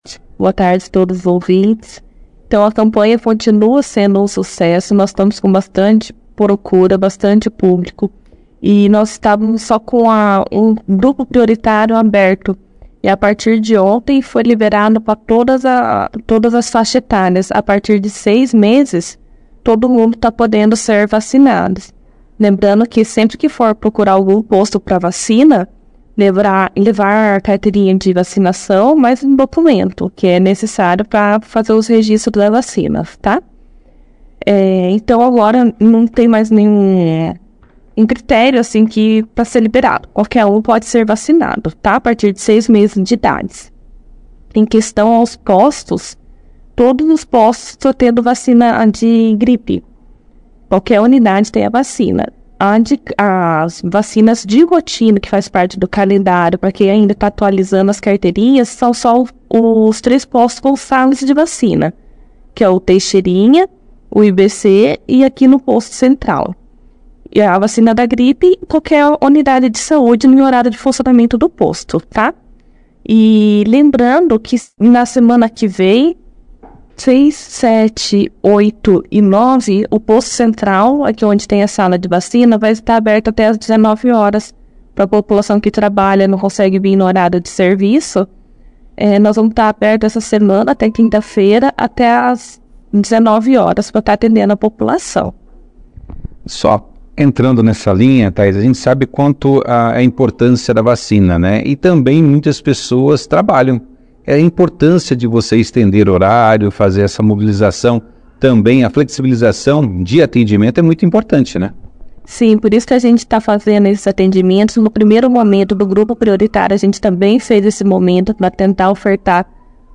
participou da edição deste sábado, 4 de maio, do jornal Operação Cidade